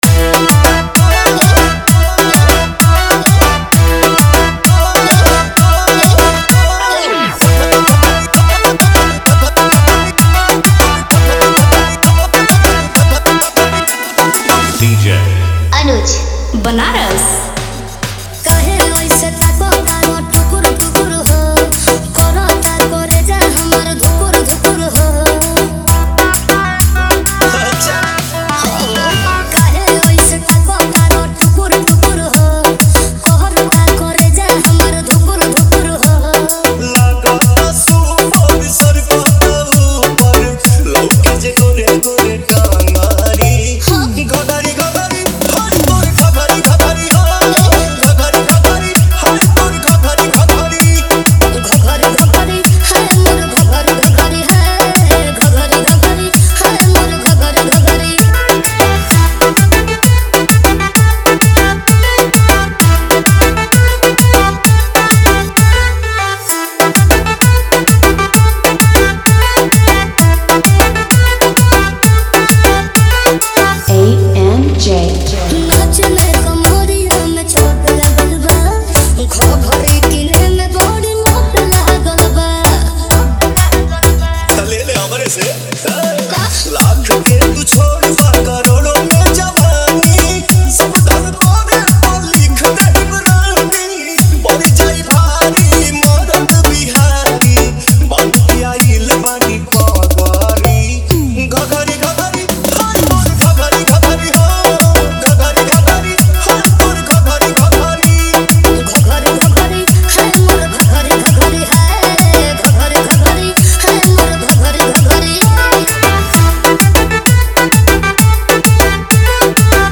वोकल: हिट भोजपुरी सिंगर
कैटेगरी: डांस मिक्स, देसी धमाका
ड्यूरेशन: फुल डीजे कट वर्जन
This is an original remix